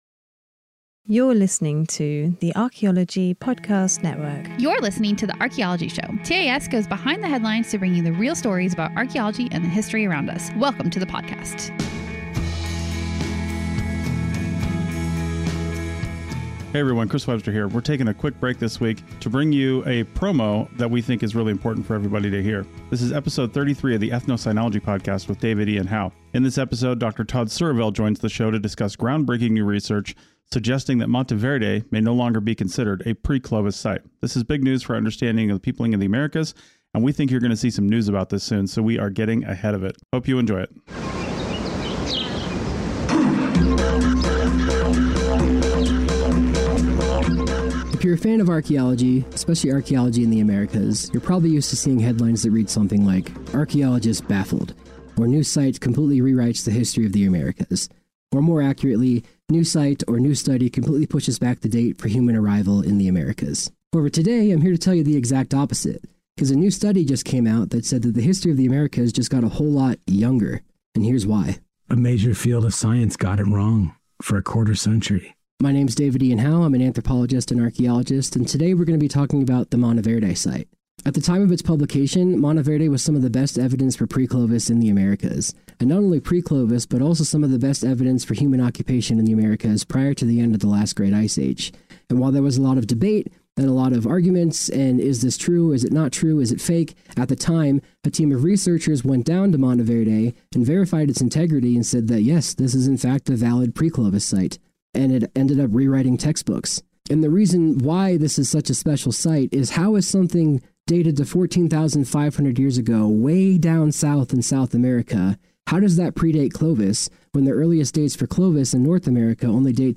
We will interview people from around the world in a variety of topics.